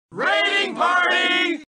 age-of-empires-ii-taunts-23-raiding-party.mp3